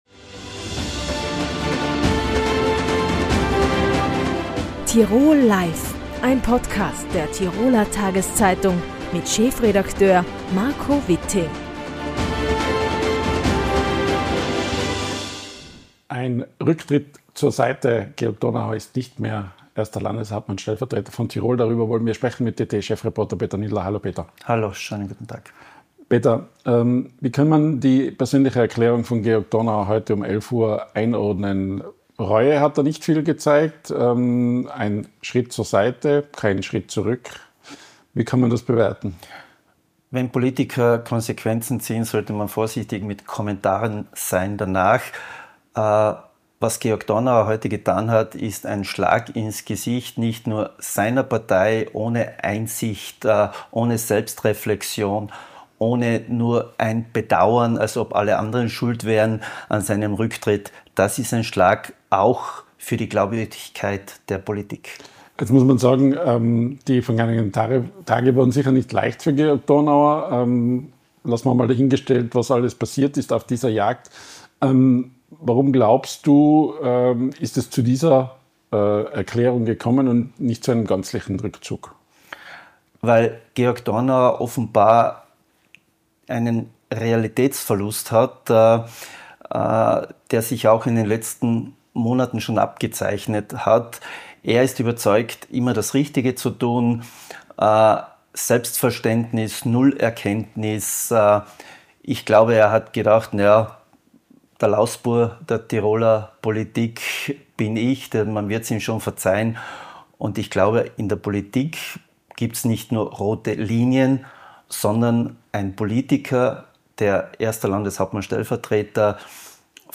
Die Analyse bei "Tirol live"